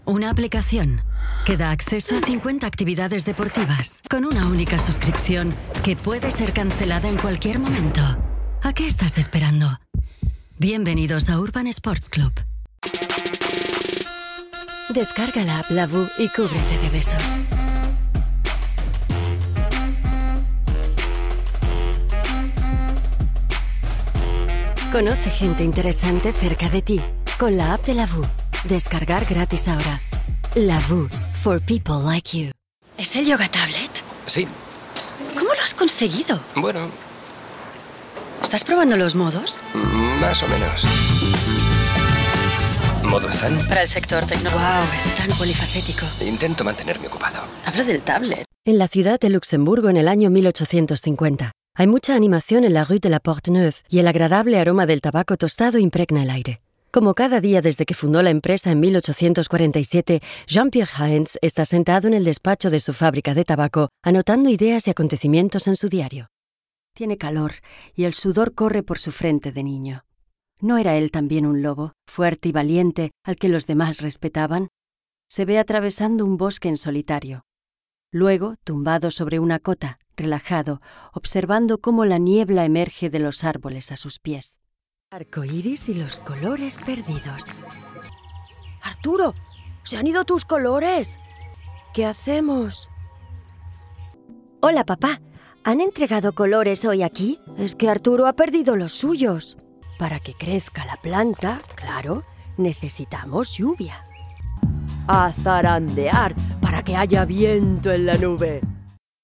Native speaker Female 30-50 lat
Her voice is clear and pleasant.
Demo lektorskie